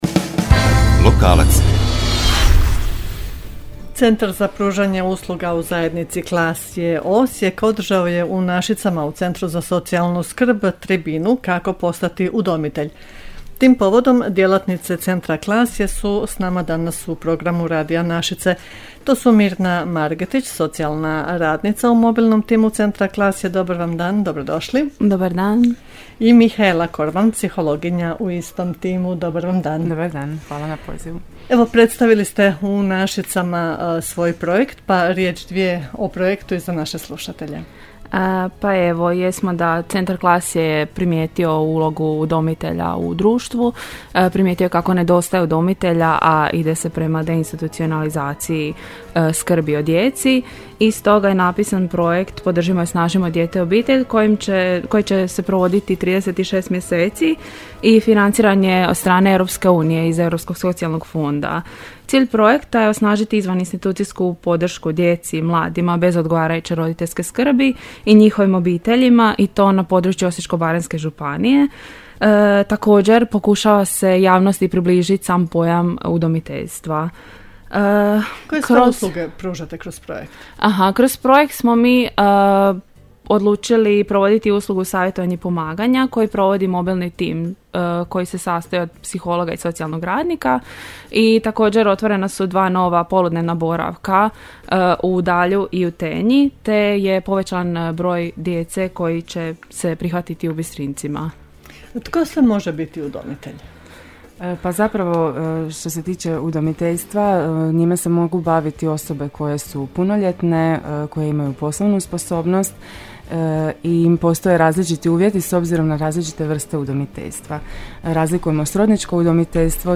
Radio_Nasice_bii0eo.mp3